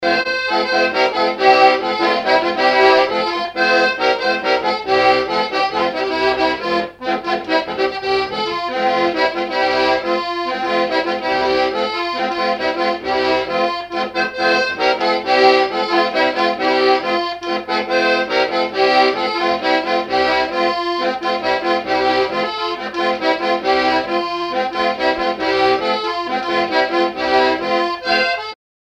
Localisation Noirmoutier-en-l'Île (Plus d'informations sur Wikipedia)
Fonction d'après l'analyste danse : branle : courante, maraîchine ;
Usage d'après l'analyste gestuel : danse ;
Catégorie Pièce musicale inédite